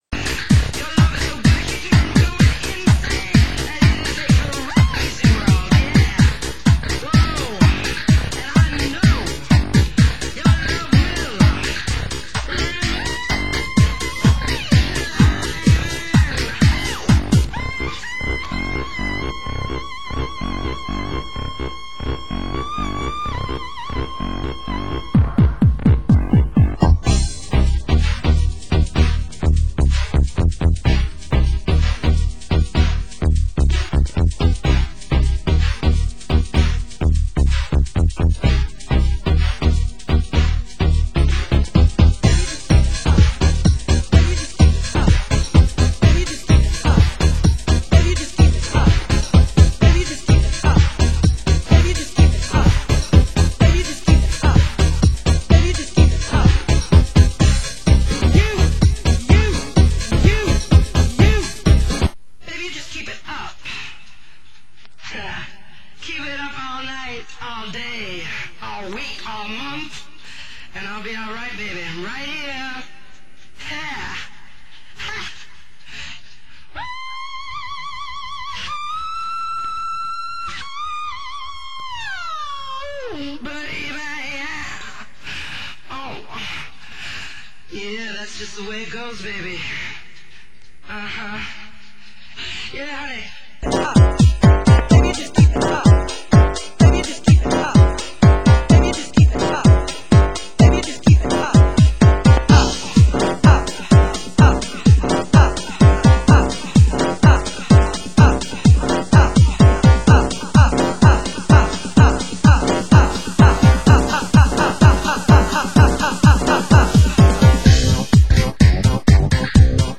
Format: Vinyl Double 12 Inch
Genre: UK House